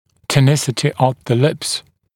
[tə(u)ˈnɪsɪtɪ əv ðə lɪps][то(у)ˈнисити ов зэ липс]тонус губ